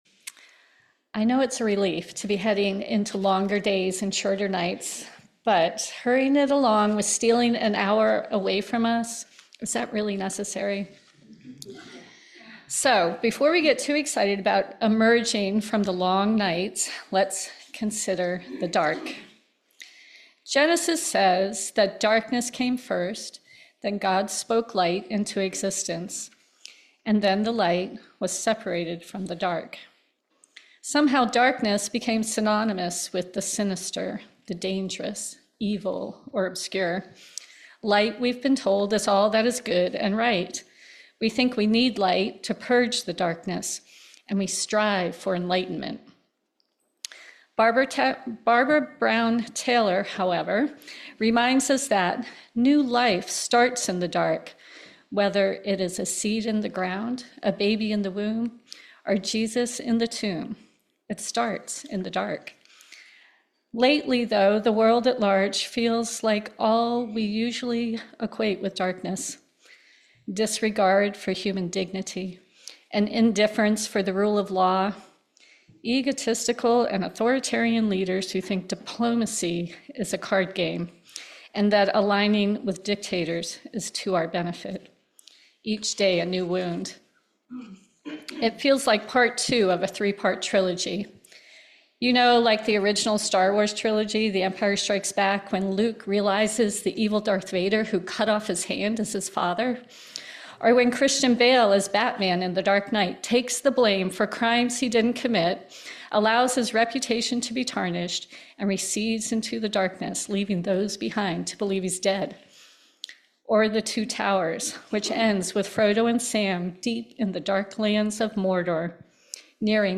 This sermon explores the transformative potential of darkness, challenging the common perception that light alone represents goodness while shadows signify evil.